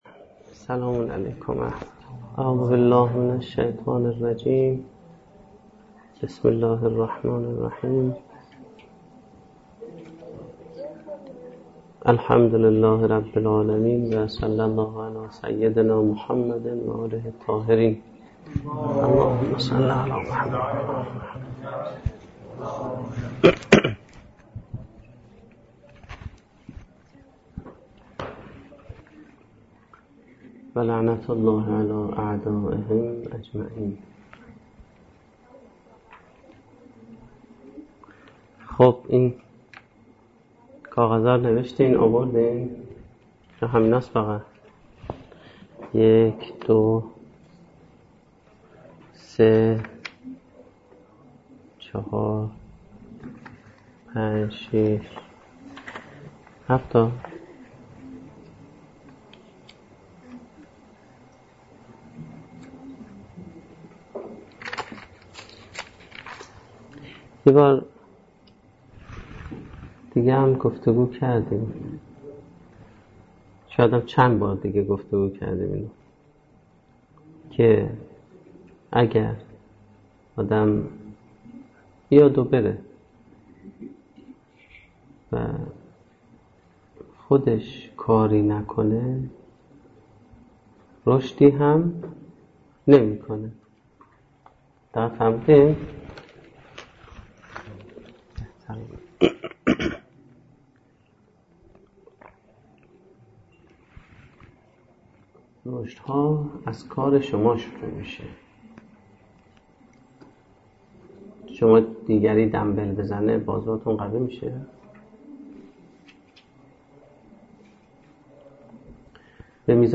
سخنرانی
در دانشگاه فردوسی